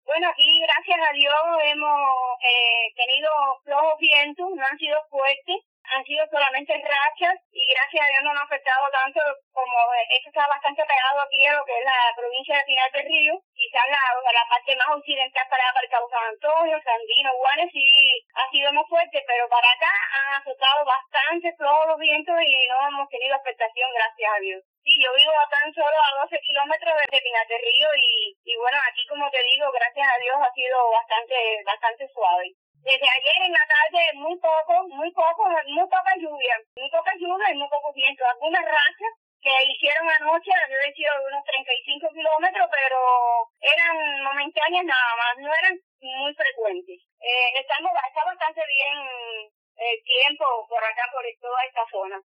A medio kilómetro de la costa está todo inundado: residente en Batabanó